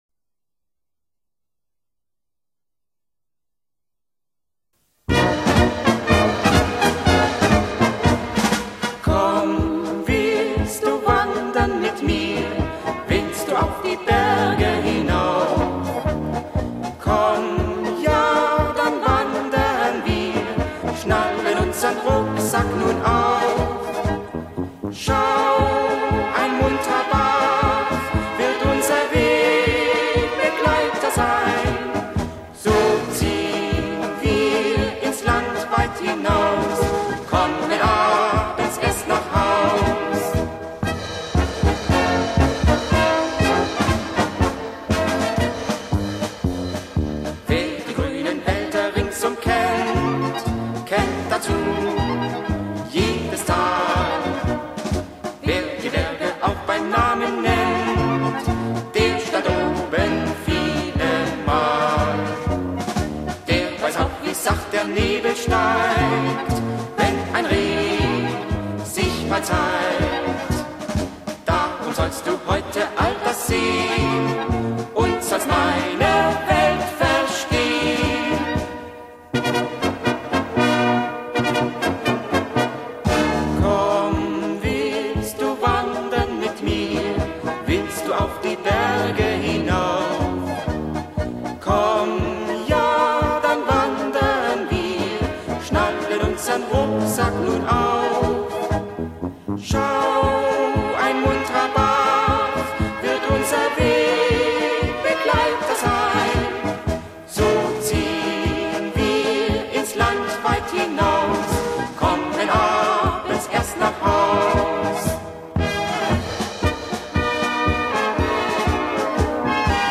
- Polka mit Gesang